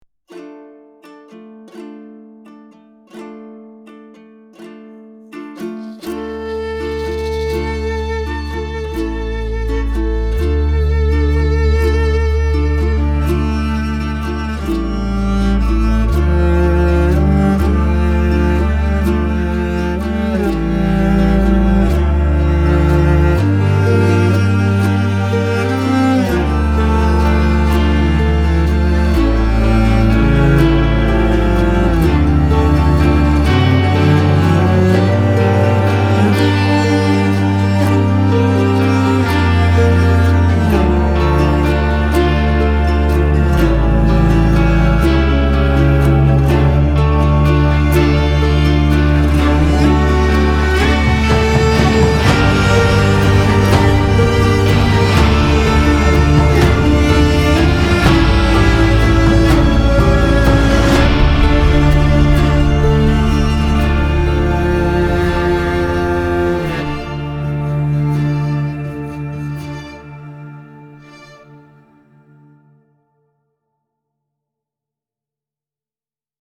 Melancholy